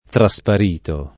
traSp#Lo], ‑spari) — pass. rem. trasparii [traSpar&-i] (meno com. trasparvi [traSp#rvi], antiq. trasparsi [traSp#rSi]); part. pass. trasparito [